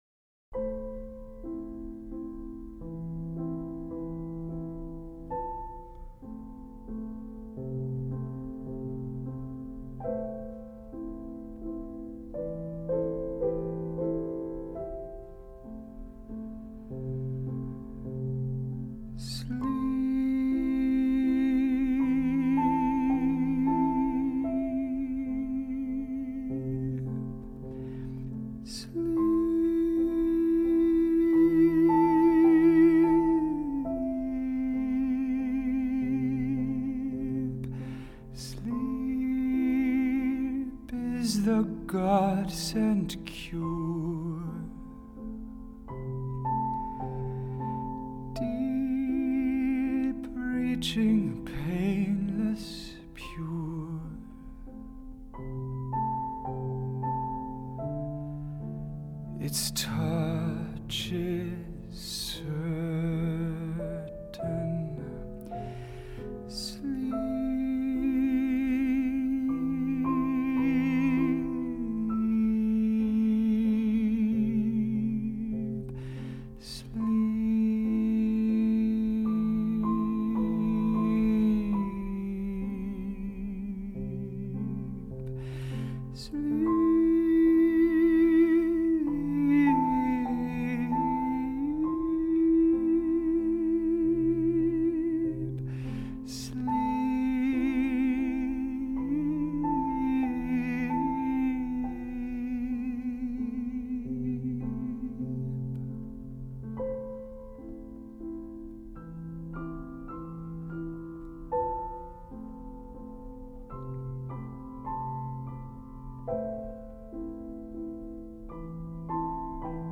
Individual Art Songs